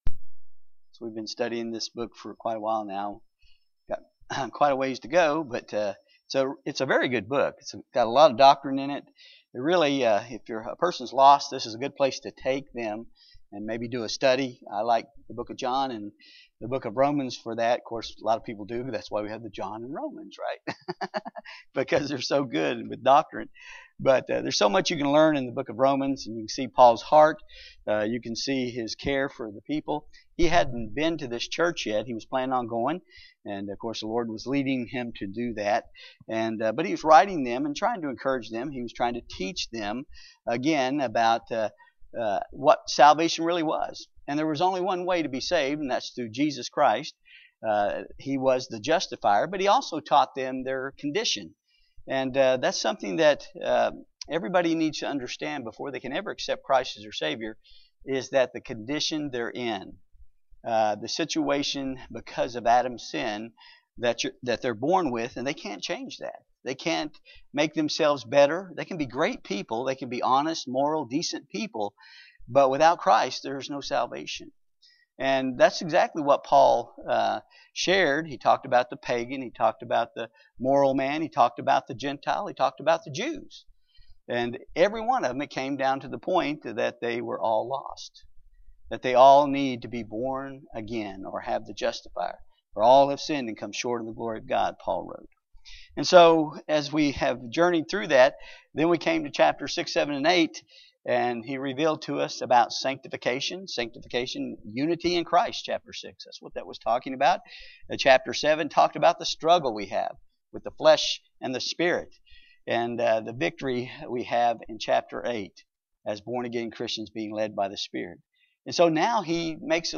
Passage: Romans 9:6-13 Service Type: Wednesday Bible Study « God’s Work